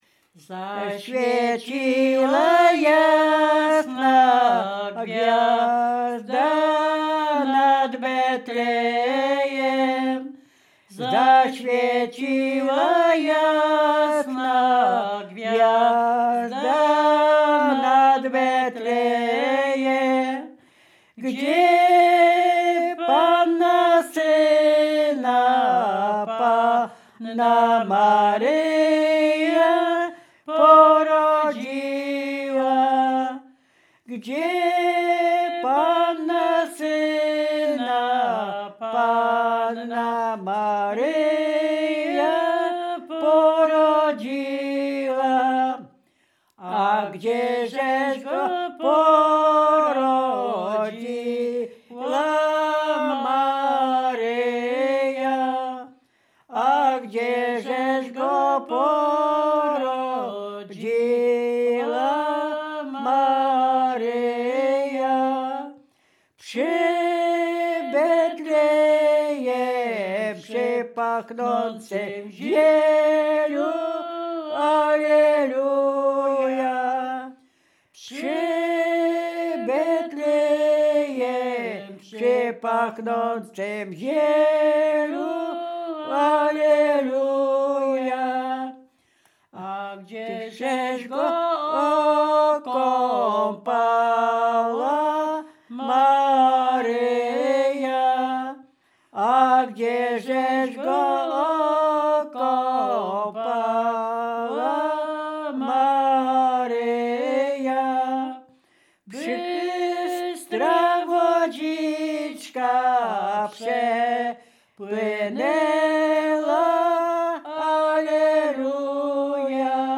Dolny Śląsk
Kolęda